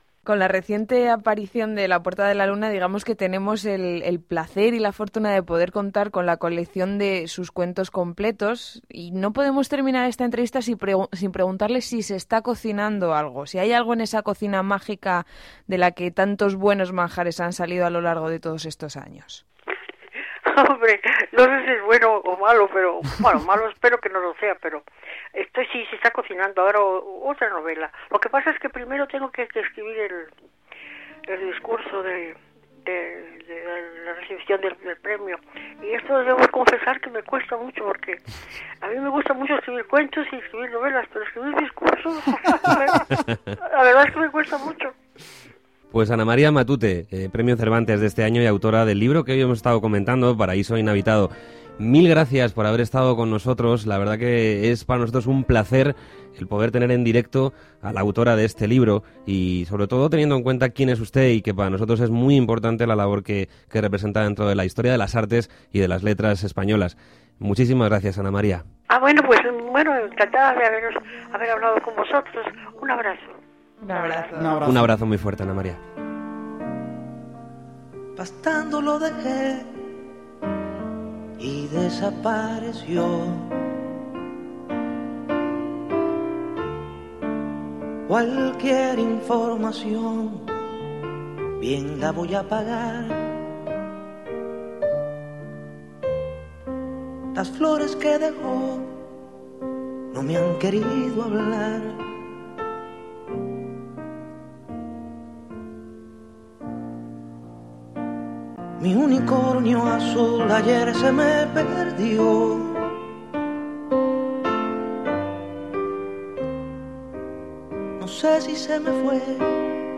Entrevista a Ana María Matute: un regalo para el día del libro